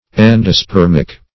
Search Result for " endospermic" : The Collaborative International Dictionary of English v.0.48: Endospermic \En`do*sper"mic\, a. (Bot.)